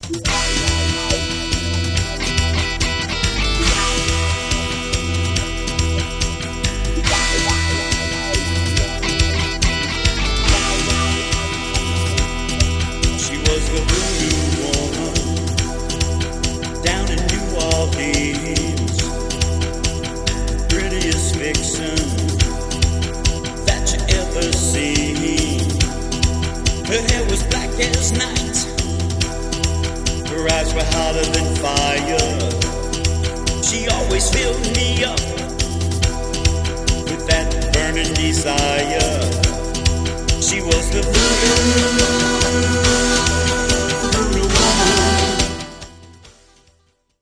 Real Audio-40Kbps mono
A heavy New Orleans funk/rock song